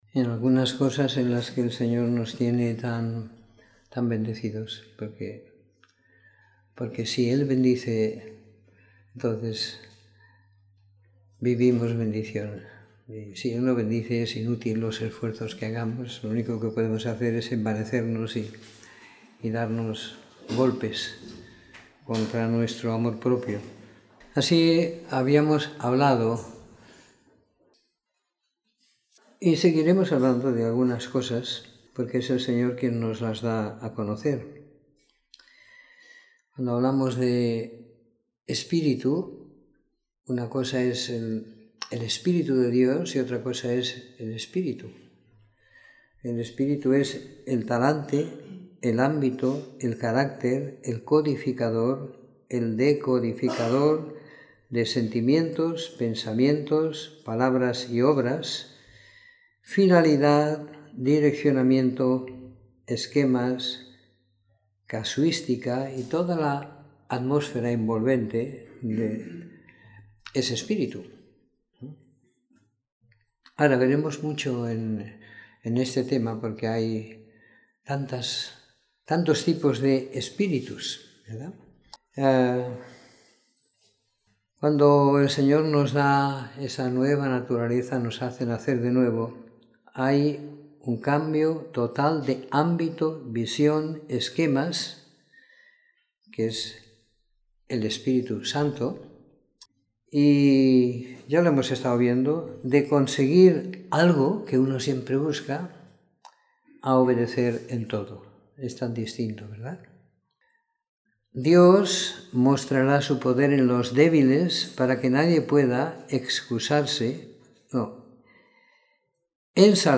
En este sermón veremos algo de la diferencia entre el Espíritu de Dios y el espíritu.